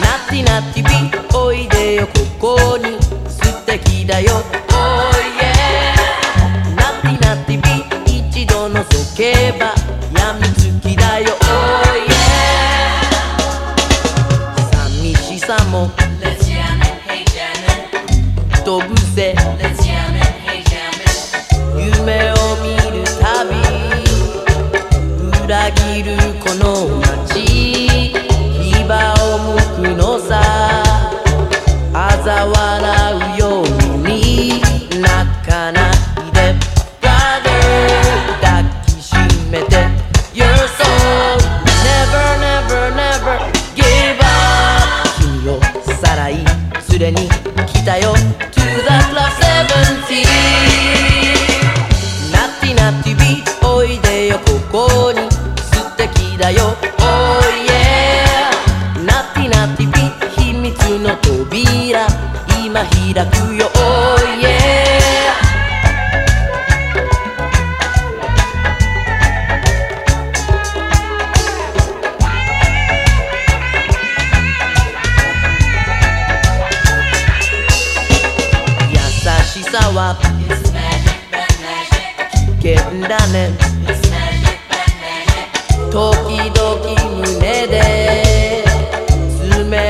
SOUL / SOUL / 70'S～ / COUNTRY SOUL / RHYTHM & BLUES
カントリー・フレーヴァー漂う
ブルーグラス＆ファンク・ビートを交えた